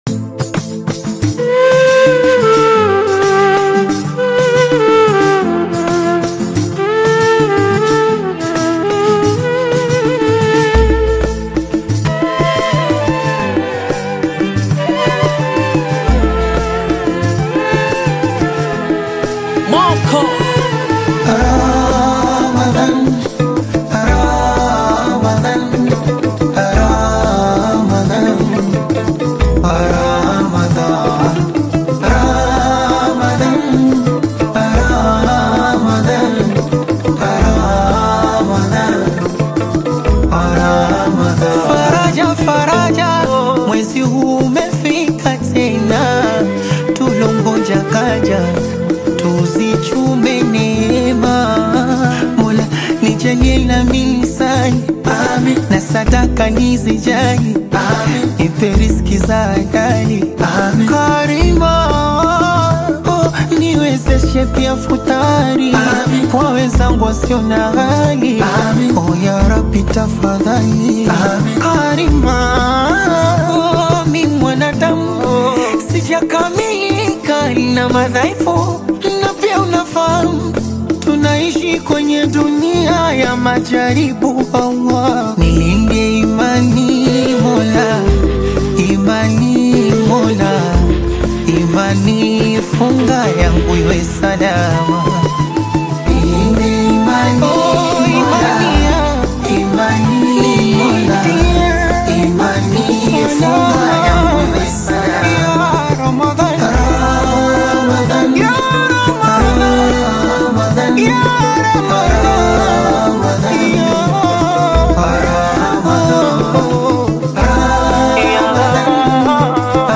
qaswida